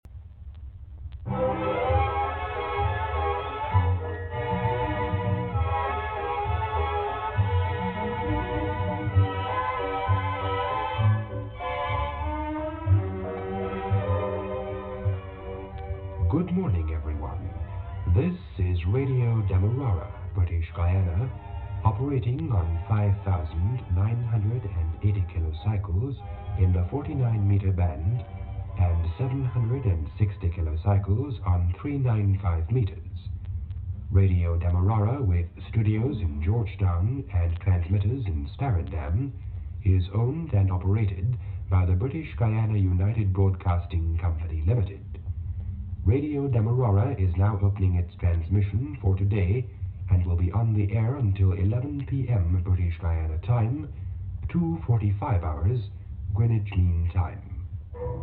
Station ID Audio